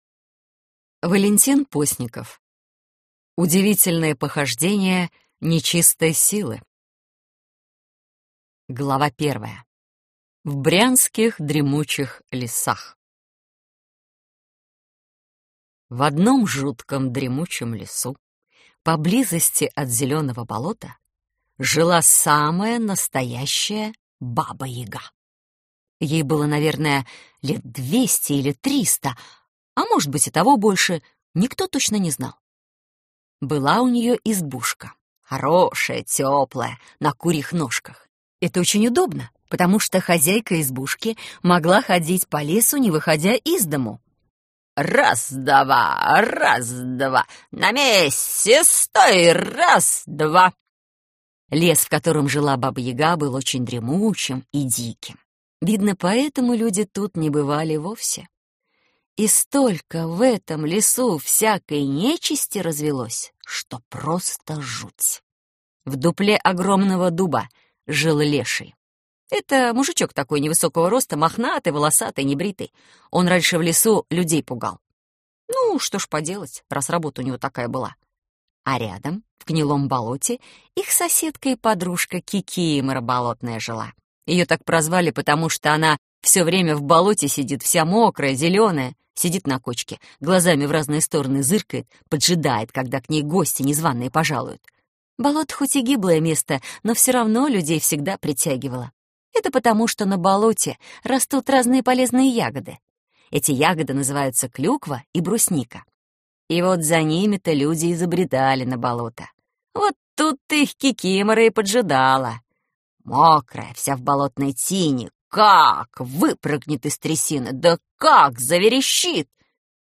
Аудиокнига Удивительные похождения Нечистой Силы | Библиотека аудиокниг